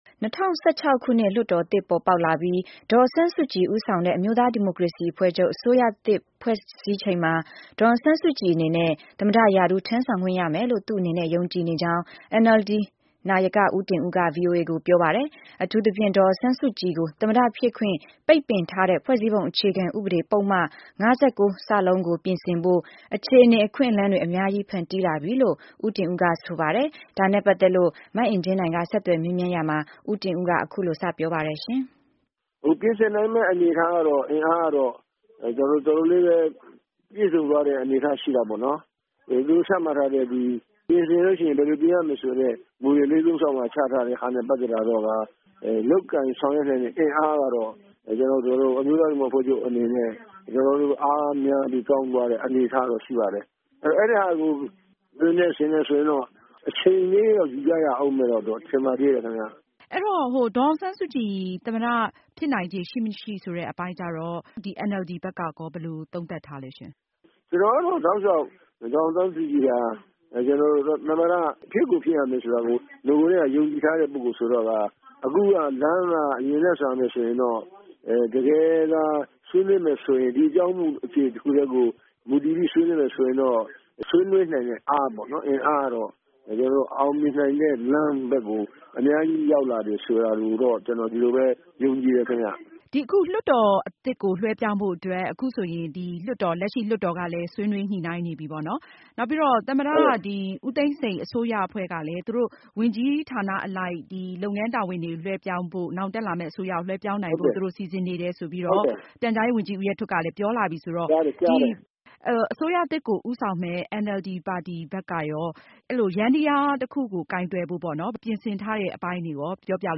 NLD နာယကကြီး ဦးတင်ဦးနဲ့ ဆက်သွယ်မေးမြန်းခန်း